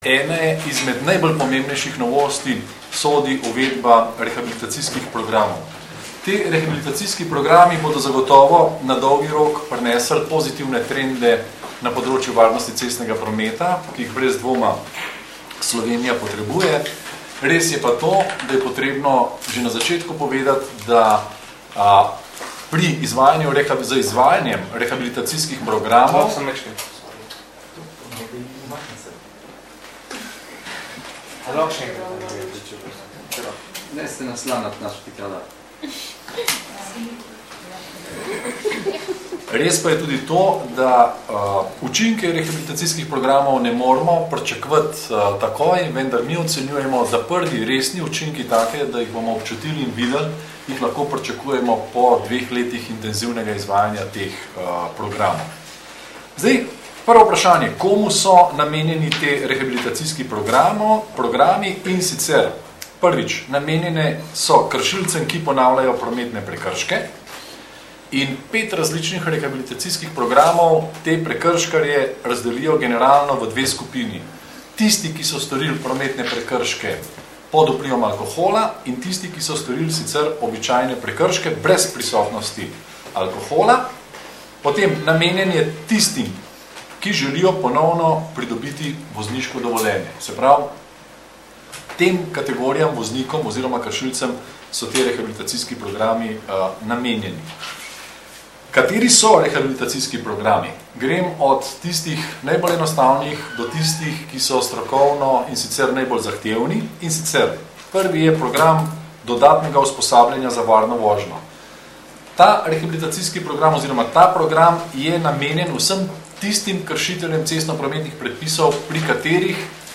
V prostorih Javne agencije RS za varnost prometa je 30. septembra 2011 ob 10. uri potekala novinarska konferenca o rehabilitacijskih programih za voznike prekrškarje, ki se začnejo izvajati po 1. oktobru 2011.